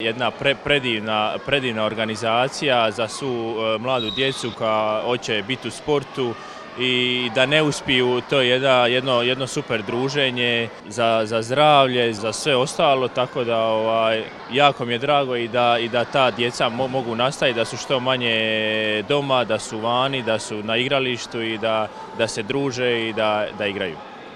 ZAGREB - Mladi sportaši i ljubitelji sporta okupili su se na terenima Nogometnog centra Šalata u Zagrebu gdje je službeno započela ovogodišnja sezona Sportskih igara mladih, malonogometnim turnirom i sportsko-ekološkim projektom Zero Waste.